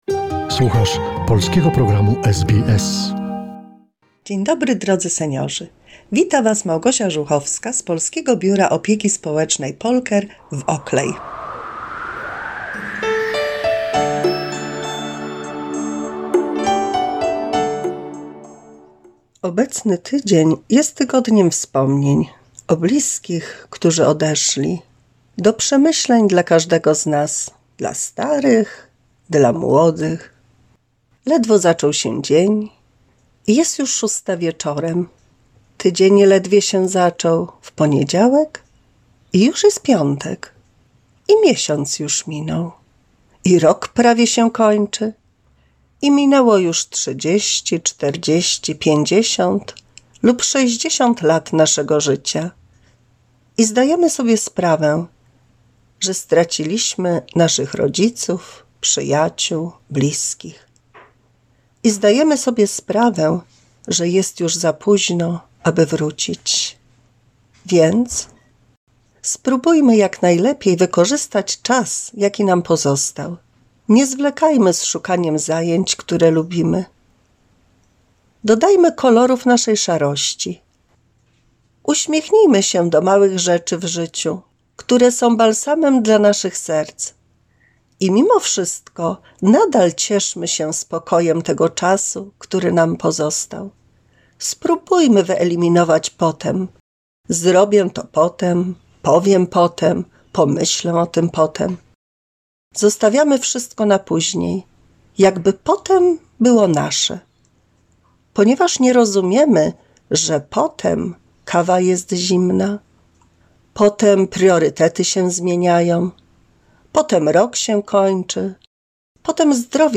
In today's broadcast you will find nostalgic content interspersed with songs about the passing and seizing the day as it is.